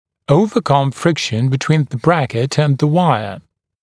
[ˌəuvə’kʌm ‘frɪkʃn bɪ’twiːn ðə ‘brækɪt ənd ðə ‘waɪə][ˌоувэ’кам ‘фрикшн би’туи:н зэ ‘брэкит энд зэ ‘уайэ]преодолеть трение между брекетом и дугой